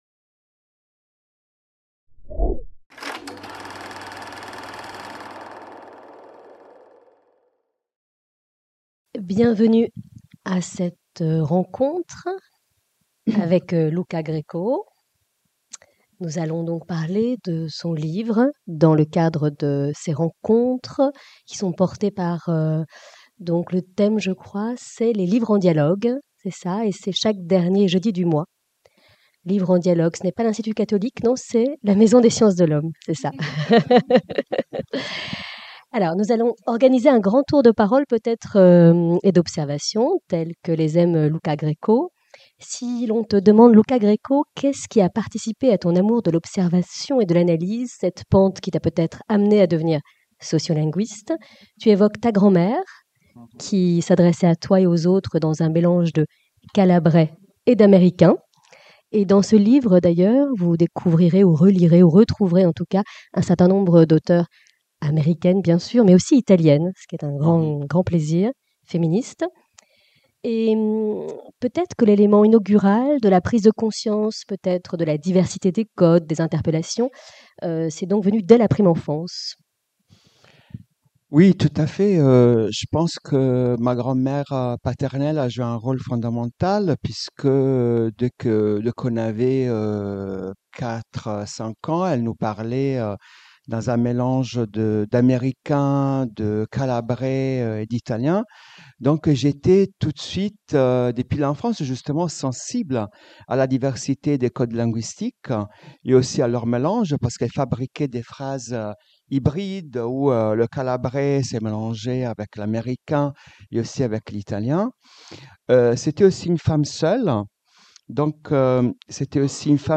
Soirée de présentation de l'ouvrage "Le corps du genre" | Canal U
qui s'est tenue le 25 septembre dans le Forum de la FMSH